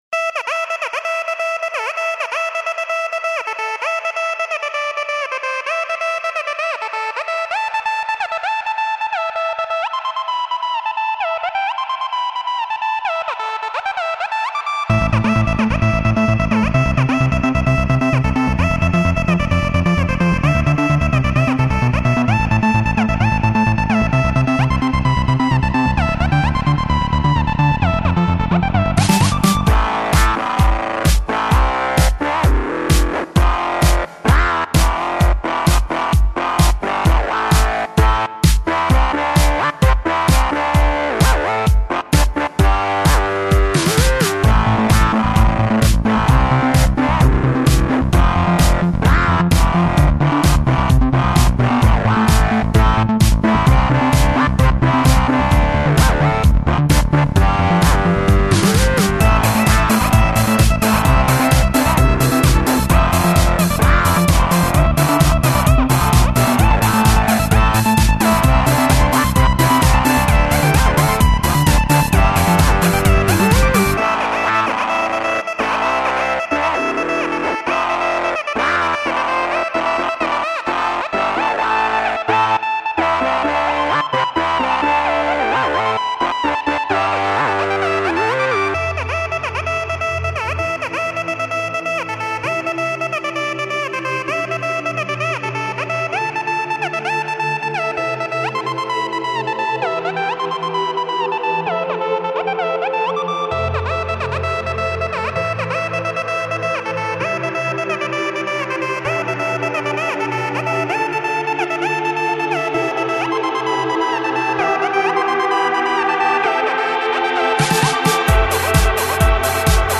ambience.mp3